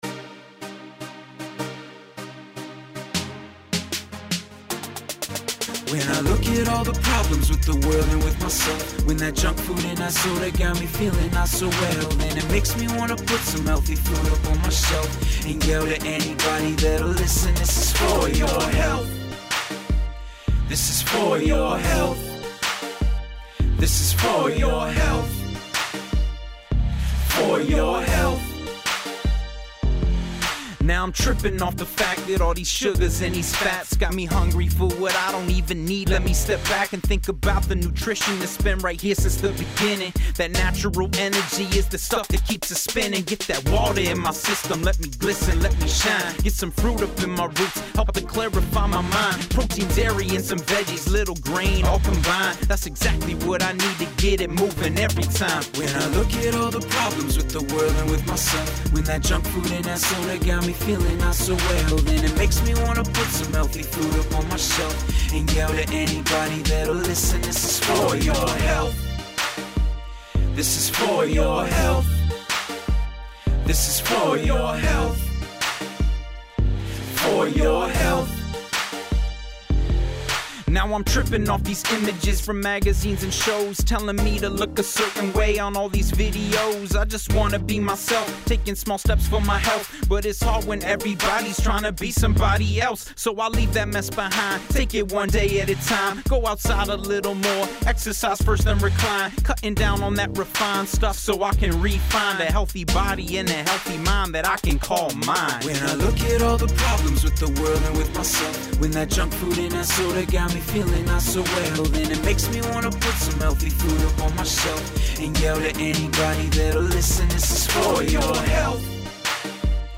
fun, high energy song